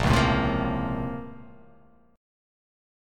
BbmM9 chord